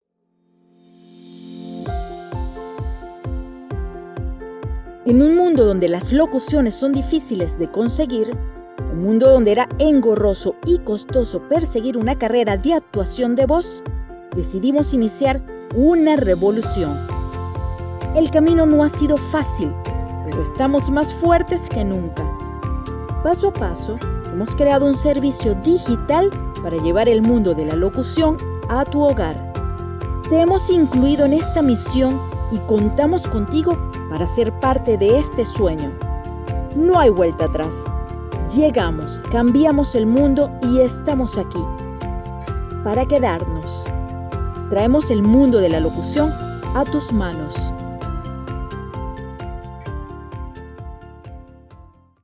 Locutora audaz y versátil
Sprechprobe: Industrie (Muttersprache):
Clear voice with excellent tone and modulation.